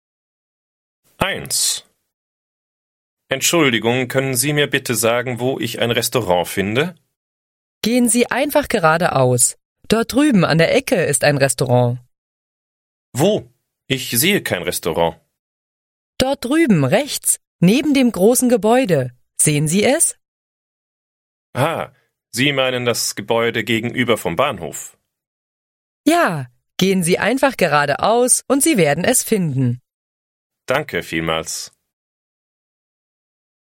A simple dialogue which takes place in a restaurant.